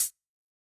UHH_ElectroHatA_Hit-22.wav